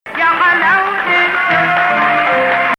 Ajam 4